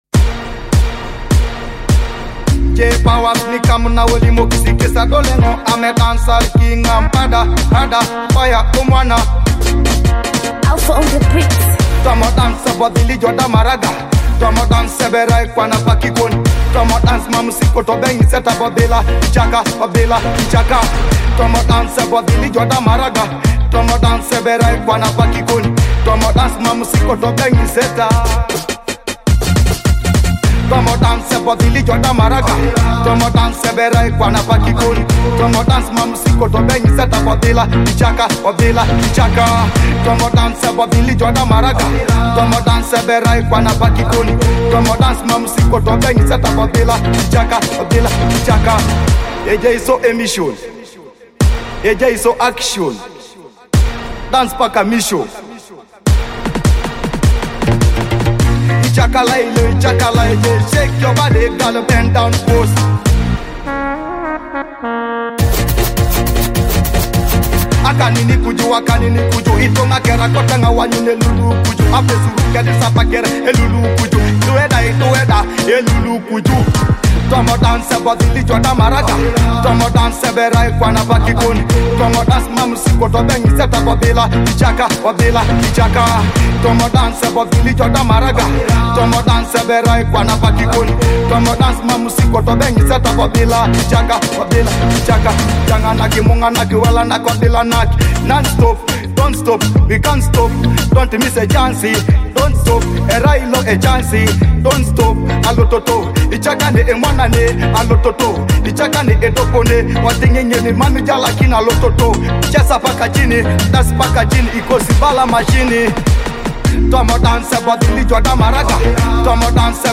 Teso hits
a vibrant, energetic Teso song